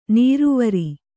♪ nīruvari